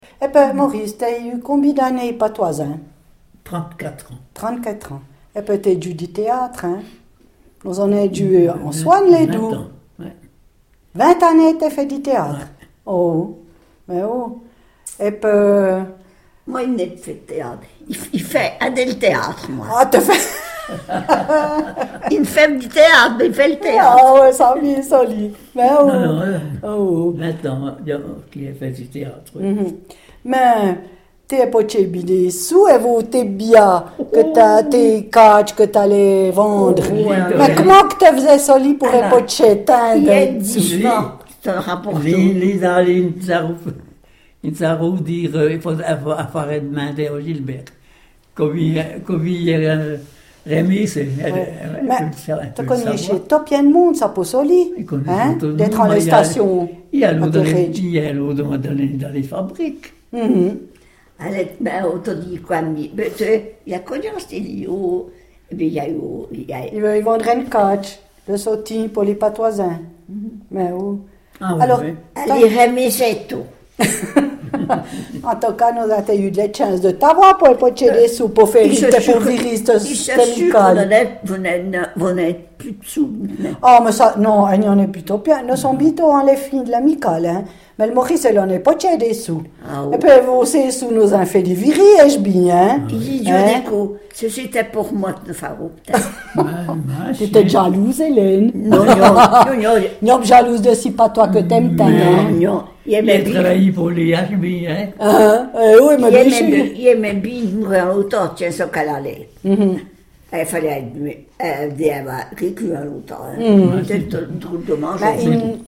parlent le patois du Val Terbi.